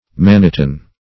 Search Result for " mannitan" : The Collaborative International Dictionary of English v.0.48: Mannitan \Man"ni*tan\, n. [Mannite + anhydrite.]